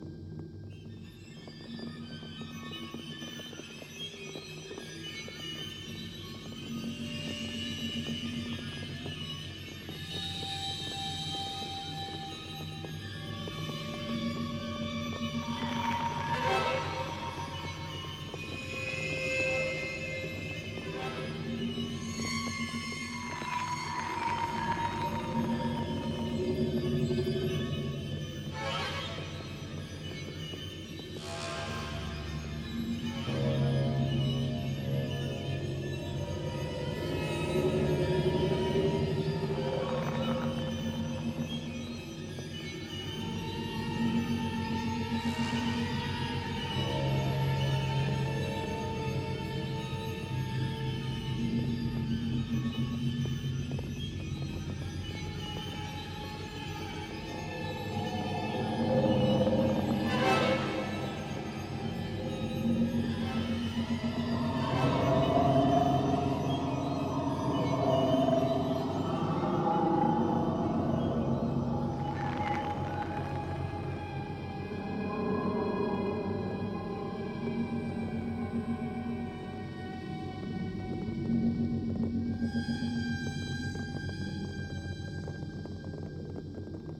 06 - Suspense